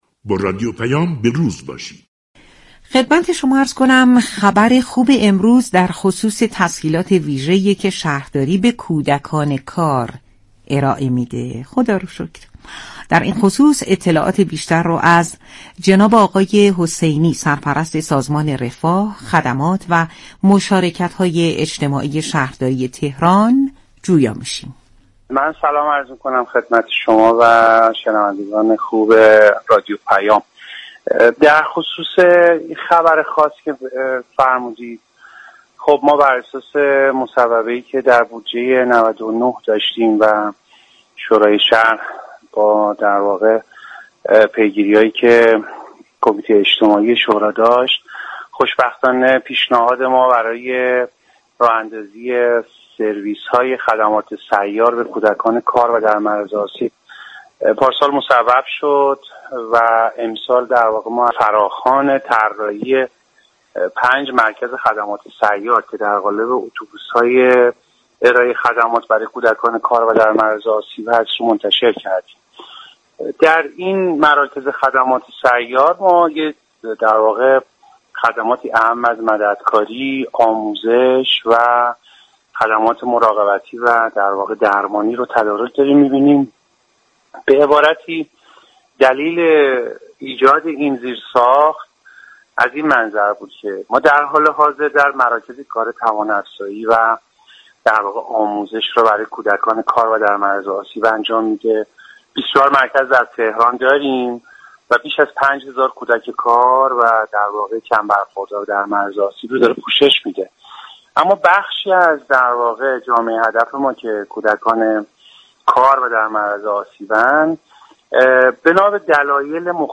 حسینی، سرپرست سازمان رفاه، خدمات و مشاركت‌های اجتماعی شهرداری تهران، در گفتگو با رادیو پیام ، از ارائه خدمات ویژه به كودكان كار و در معرض آسیب توسط شهرداری تهران خبر داد .